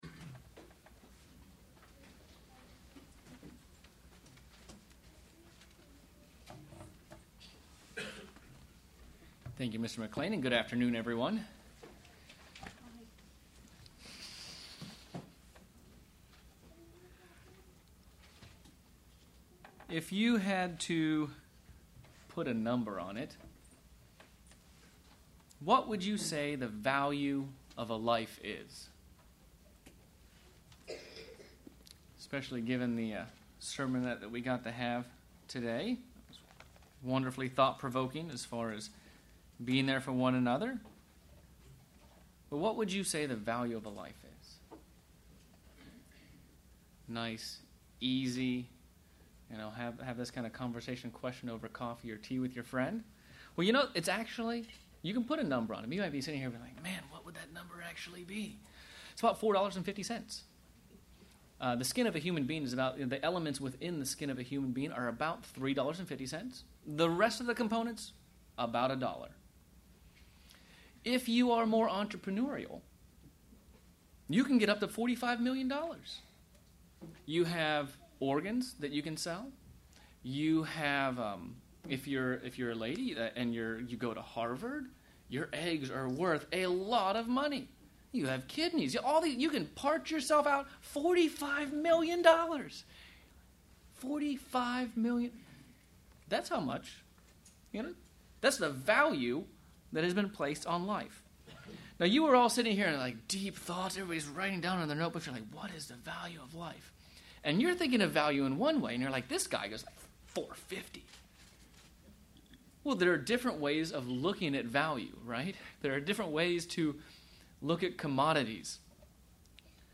This sermon explores how we often assess value and what our true value is based on how God assesses value.